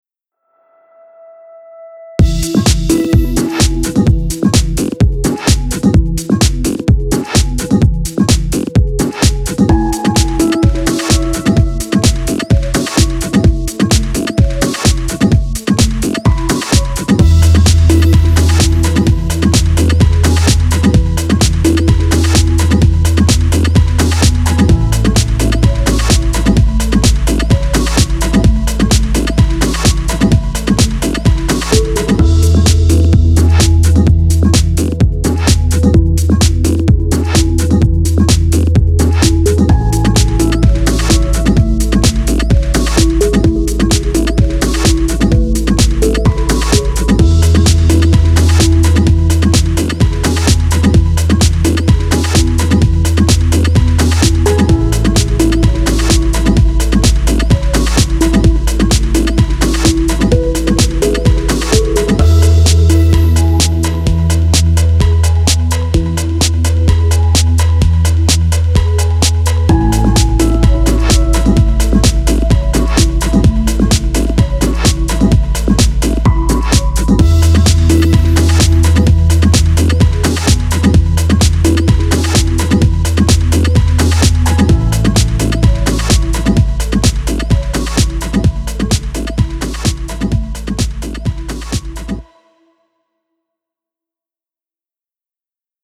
Upbeat fun music.